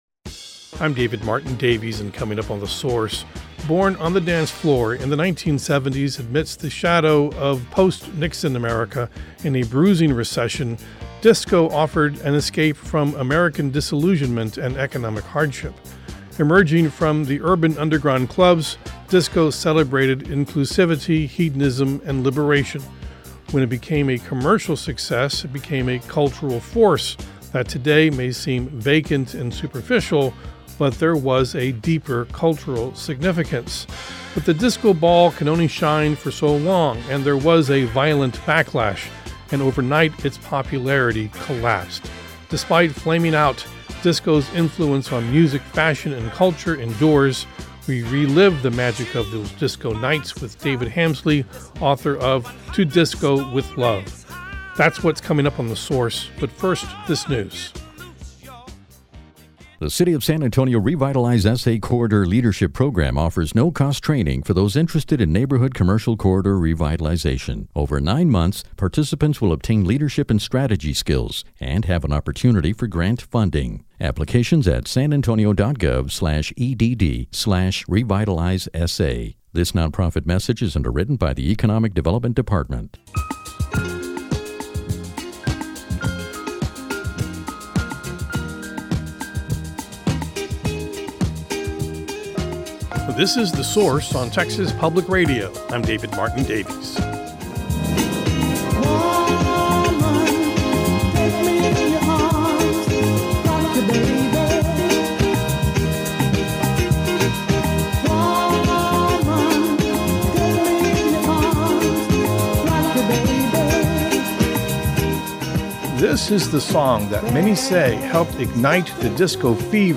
source-disco-mixx.mp3